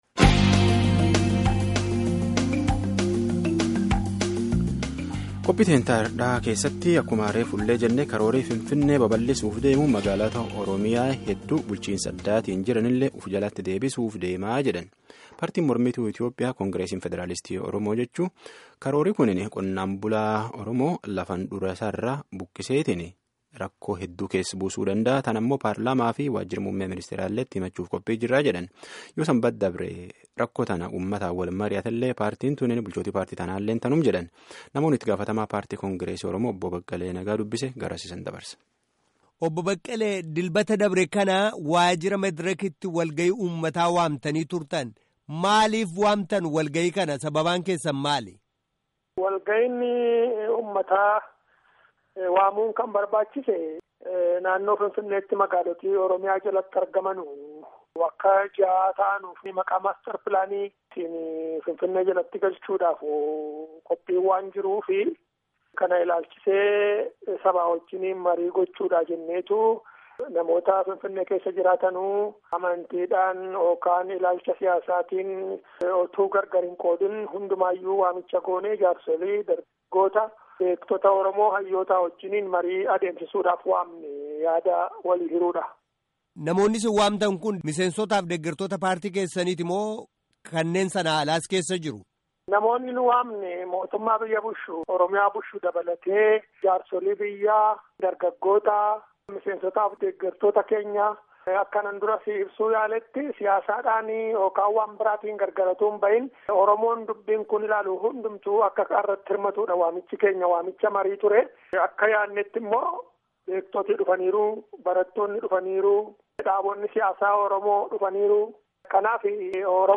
Gaaffii fi deebii gaggeeffanne armaa gadiitti caqasaa.